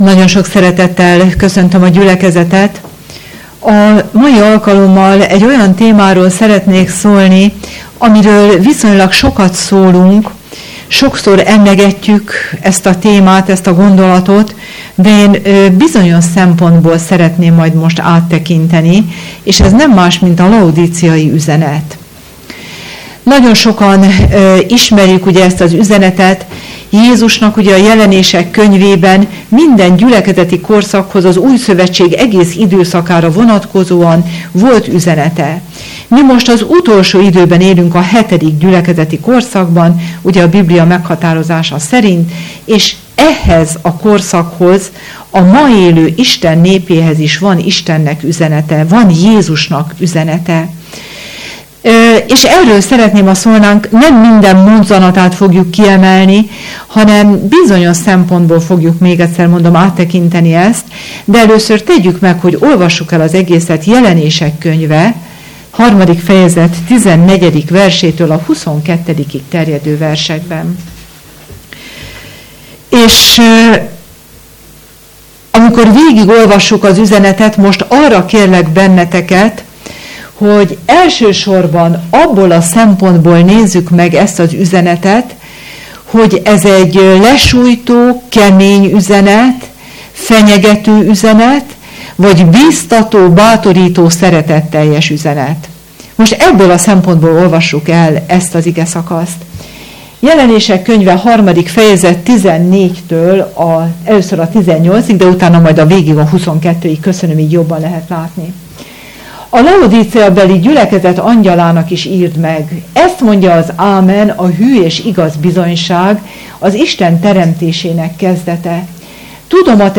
Bibliaház (Bp. - Bertalan Lajos utca)